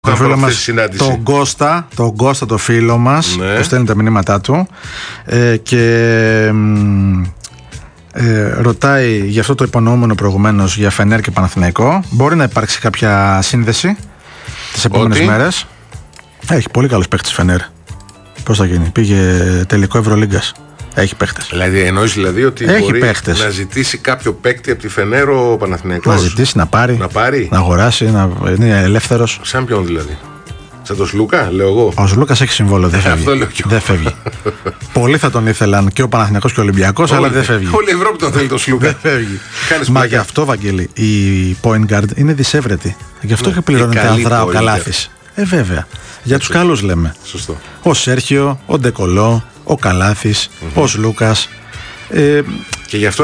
Ο Παναθηναϊκός καλοβλέπει παίκτη που την περασμένη σεζόν αγωνίζονταν στην Φενέρμπαχτσε, όπως μετέδωσε ραδιoφωνικός σταθμός.